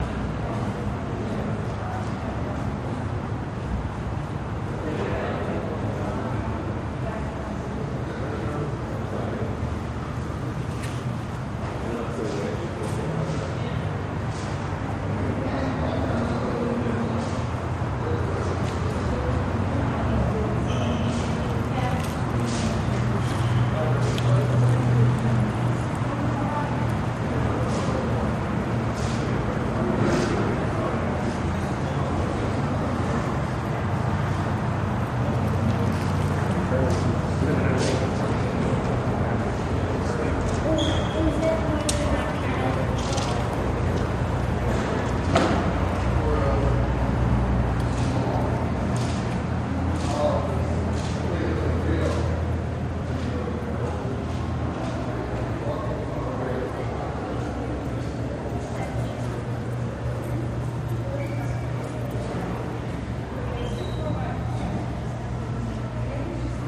Hospital Room Tone With Light Walla